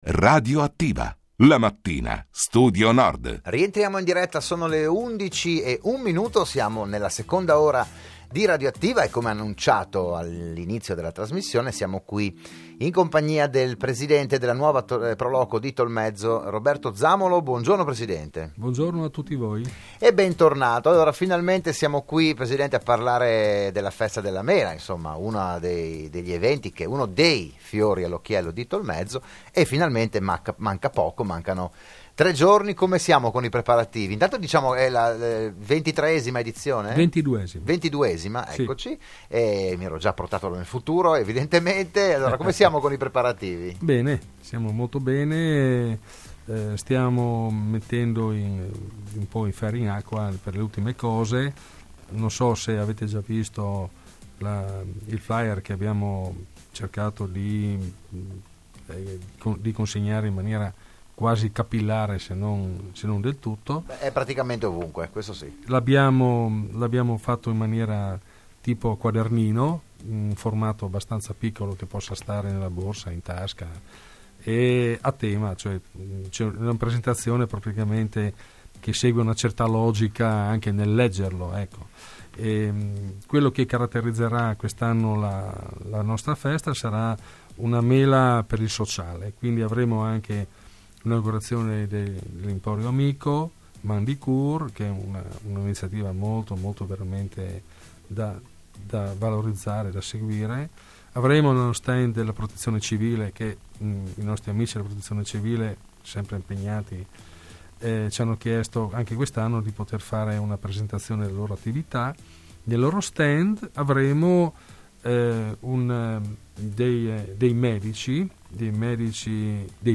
Appuntamento il 16 e 17 settembre. Il PODCAST dell'intervento a Radio Studio Nord